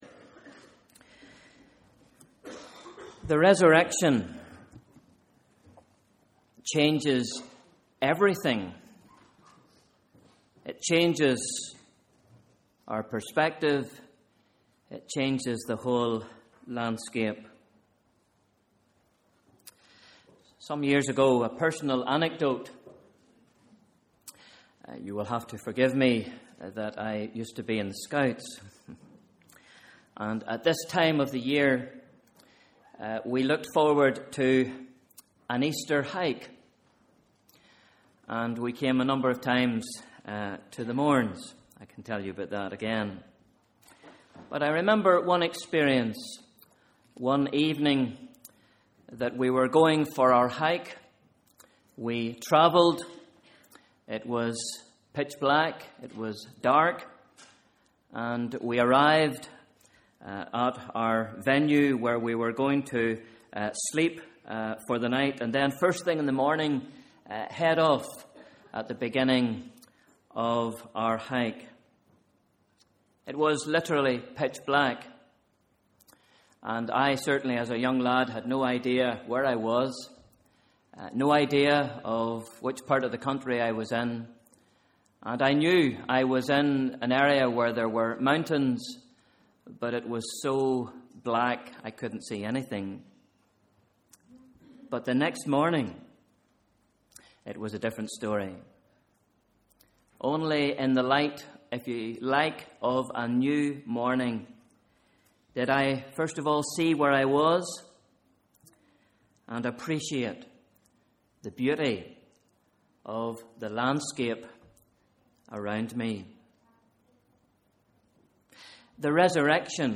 Sunday Evening Service – Easter Day (31st March 2013)